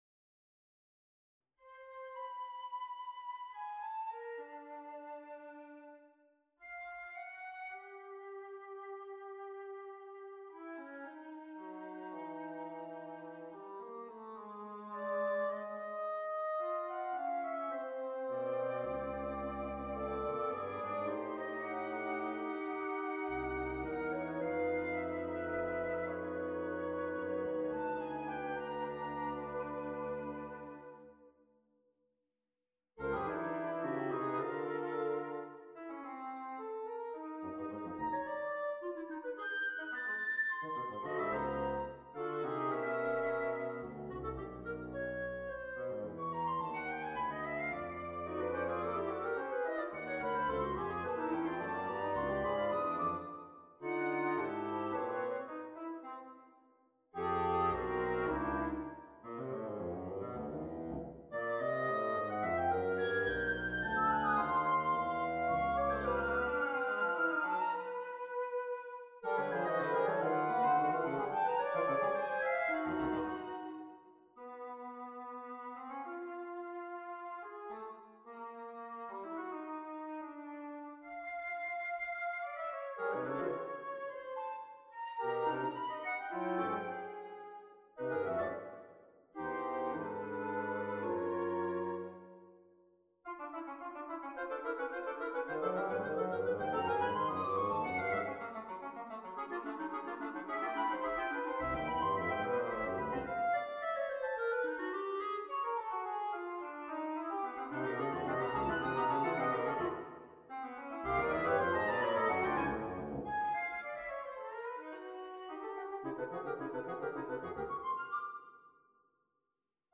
for Flute, Bb Clarinet, Cor Anglais and Bassoon
on a purpose-selected tone row
Introduzione. Largo - Andante robusto